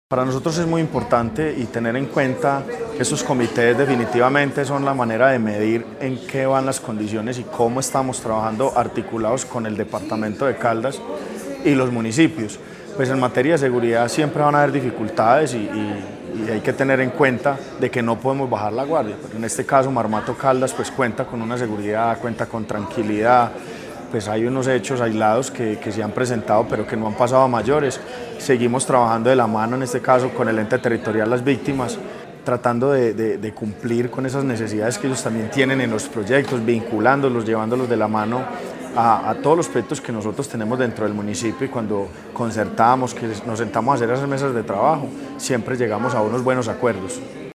Carlos Alberto Cortés, alcalde de Marmato.
Carlos-Alberto-Cortes-alcalde-de-Marmato.mp3